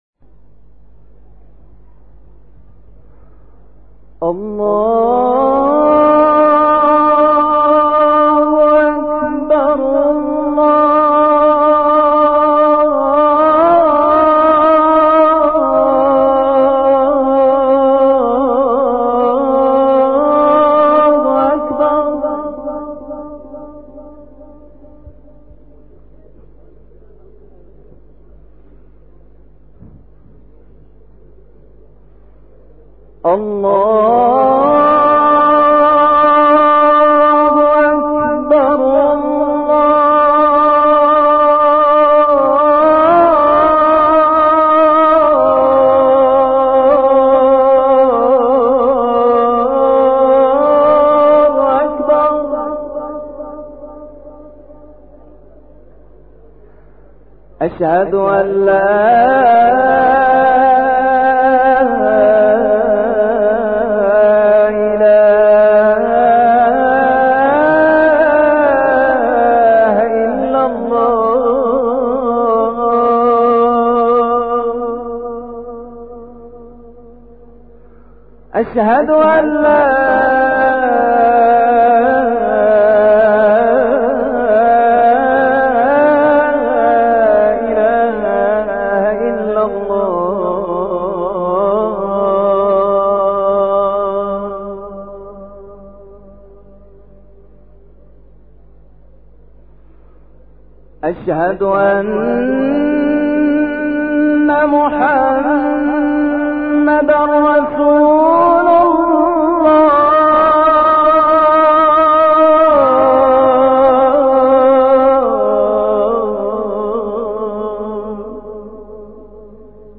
اذان (انتظار).mp3
اذان-انتظار.mp3